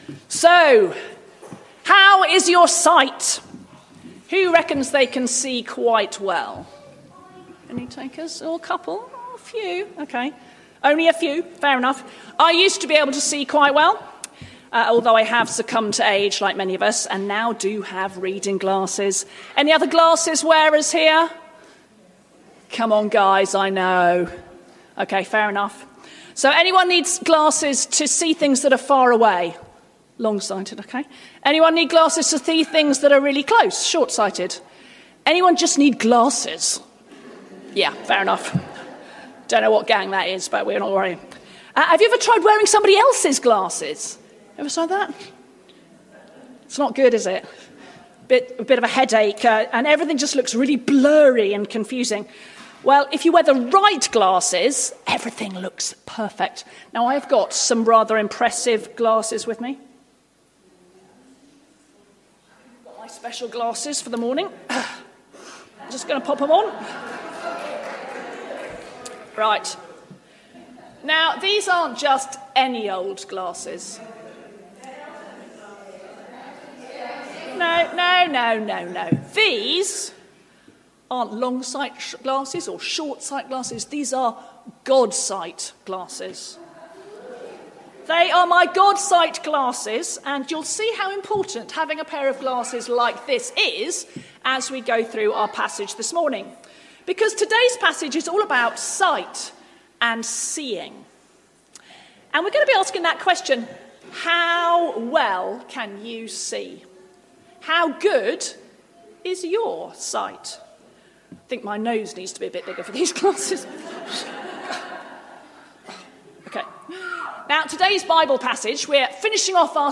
Talks, 8 December 2024